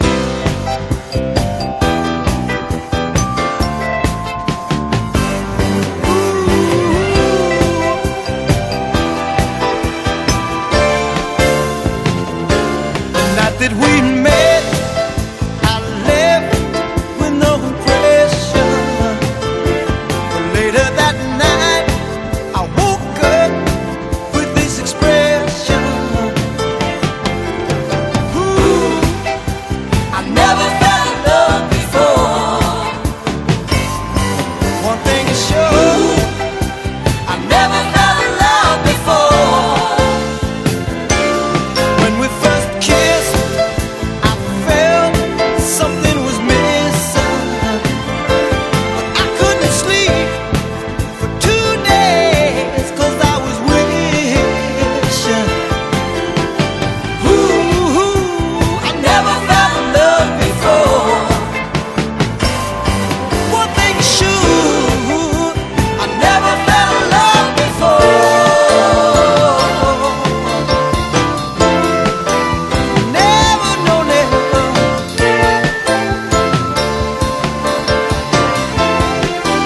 HIP HOP/R&B / BREAKBEATS / 10'S